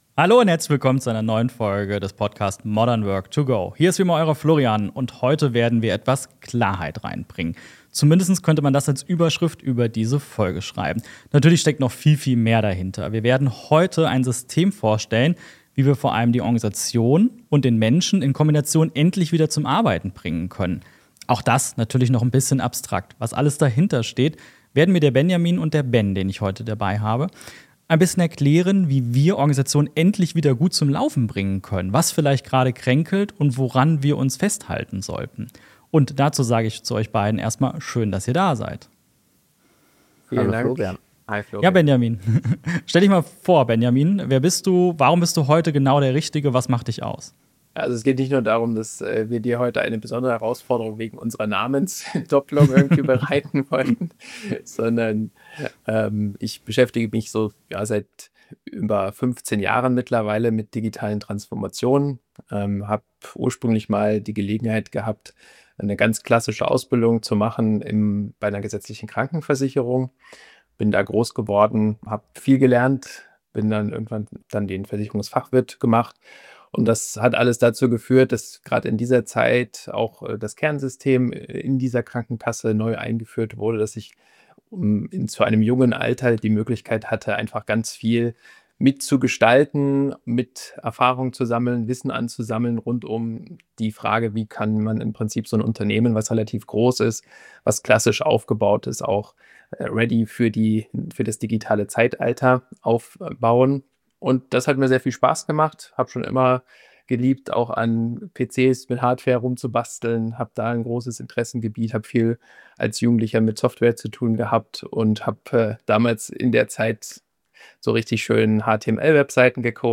#104 System Mapping – so funktionieren Unternehmen - Interview